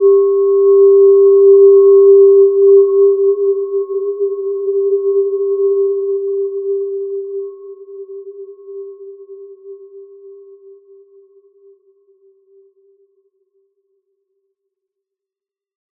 Gentle-Metallic-4-G4-p.wav